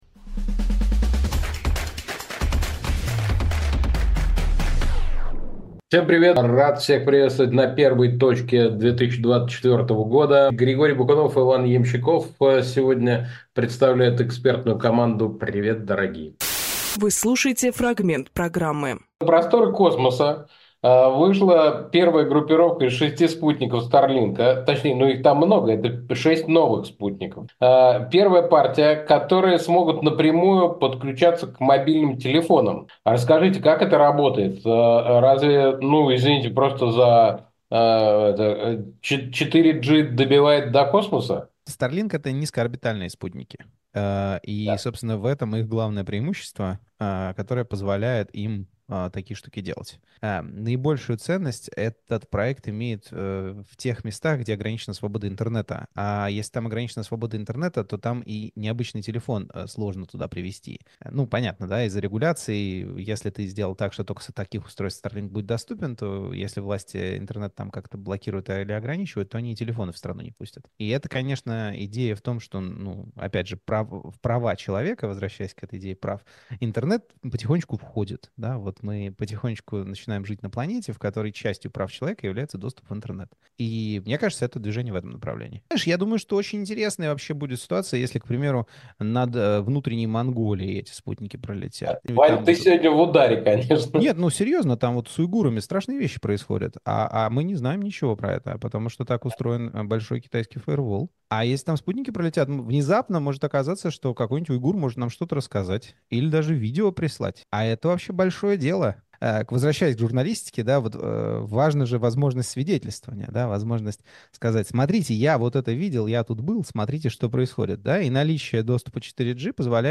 Фрагмент эфира от 07.01.24